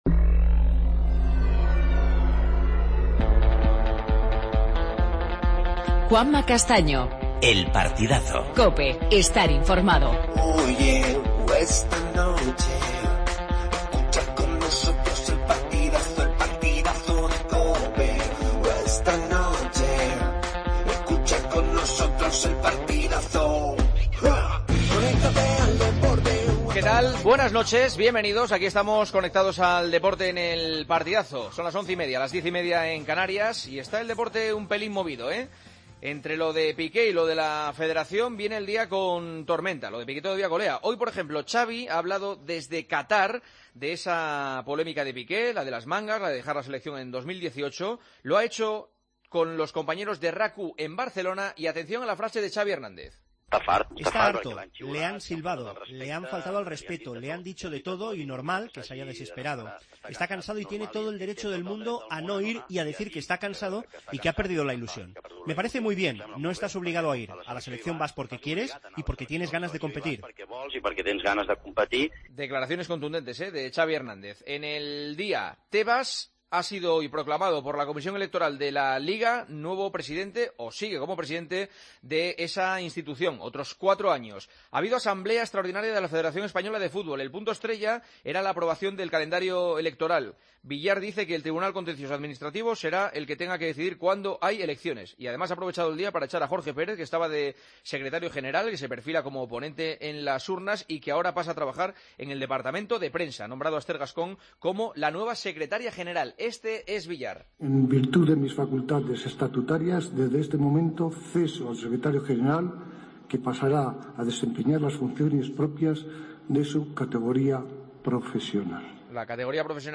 Entrevista al delantero del Barcelona, Paco Alcácer